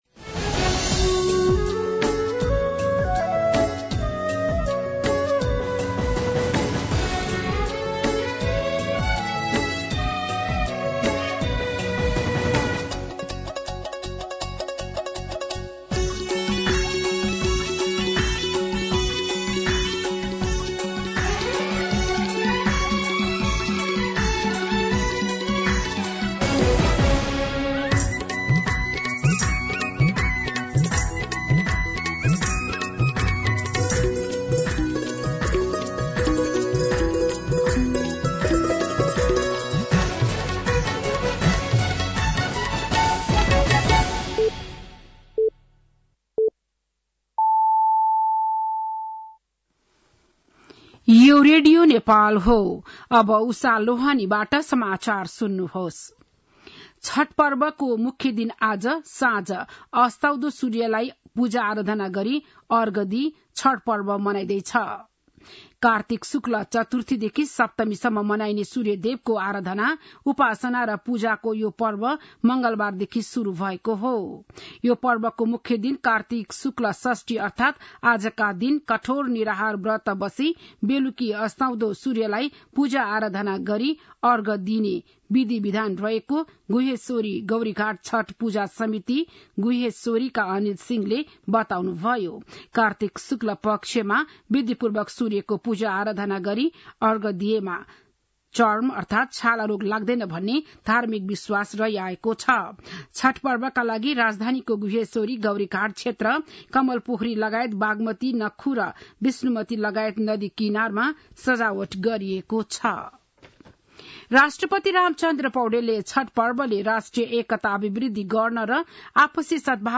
बिहान ११ बजेको नेपाली समाचार : २३ कार्तिक , २०८१